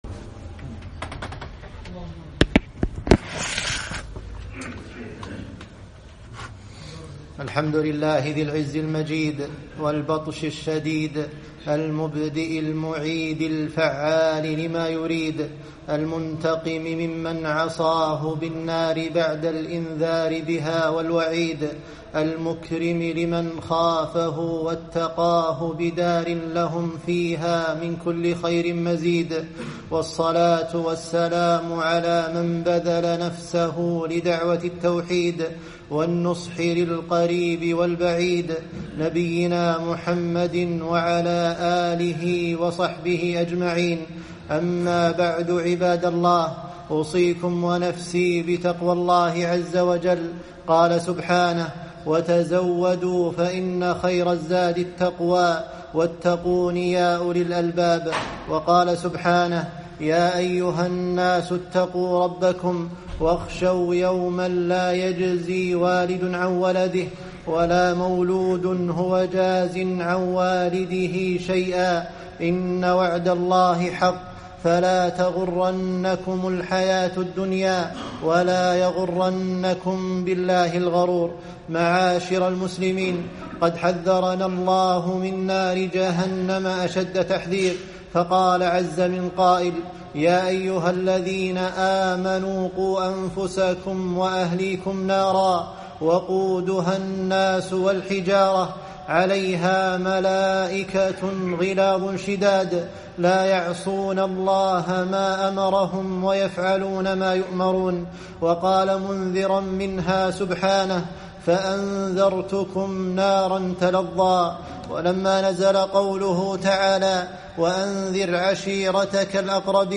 خطبة - نار جهنم وشيء من أهوالها